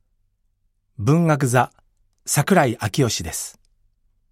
ボイスサンプルはこちら↓
ボイスサンプル